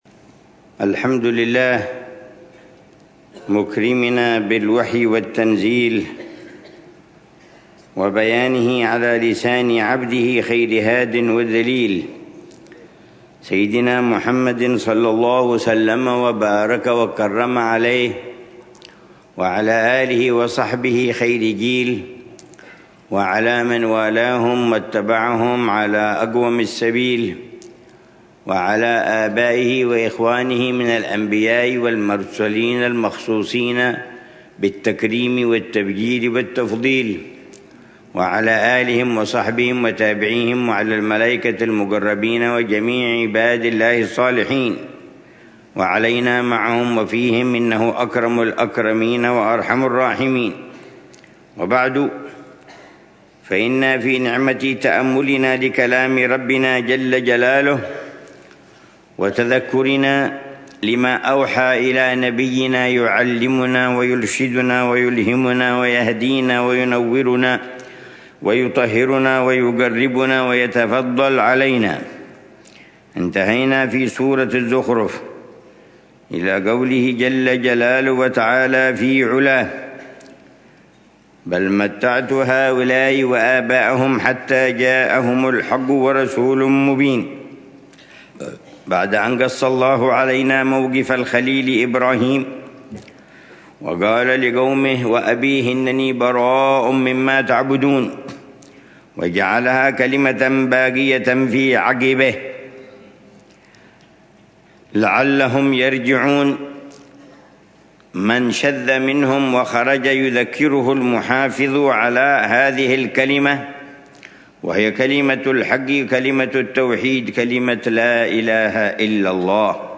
الدرس الرابع من تفسير العلامة عمر بن محمد بن حفيظ للآيات الكريمة من سورة الزخرف، ضمن الدروس الصباحية لشهر رمضان المبارك من عام 1446هـ